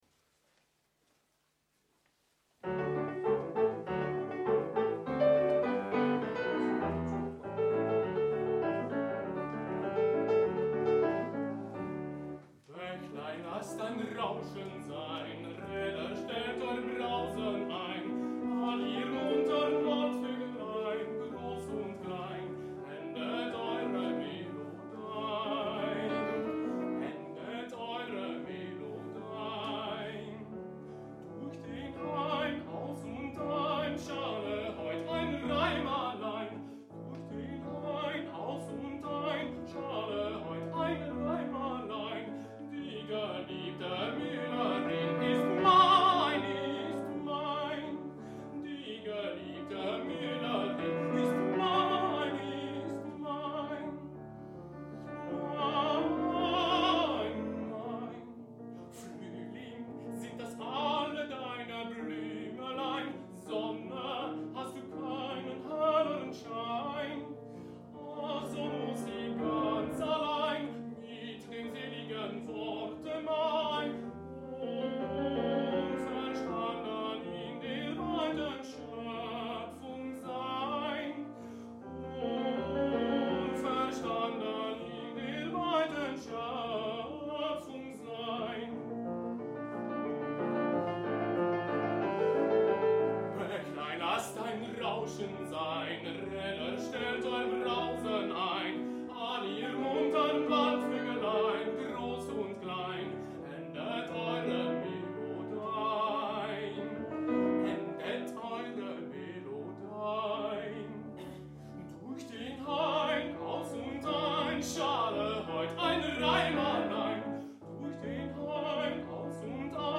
BEGELEIDING LIED EN OPERA/ OPERETTE ARIA’S